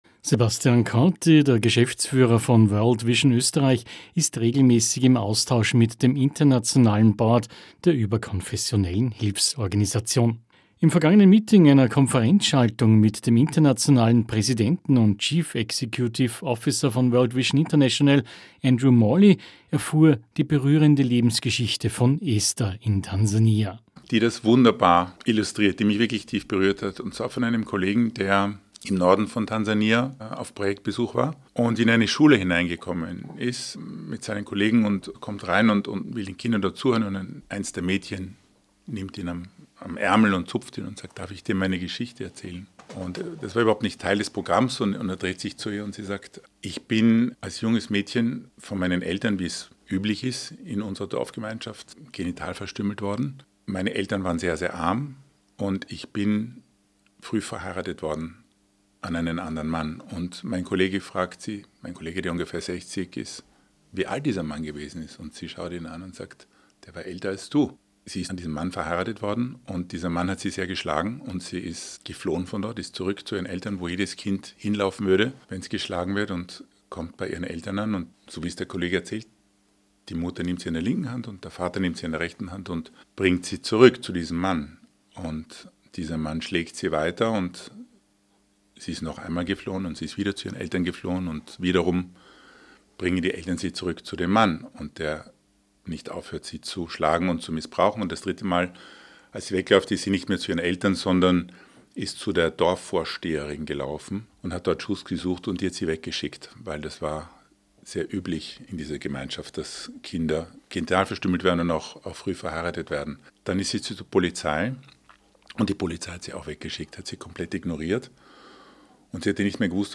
Radiobeiträge zu unserer „100 Mädchen“ Kampagne